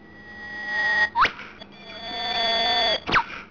Whipbird
WHIPBIRD.wav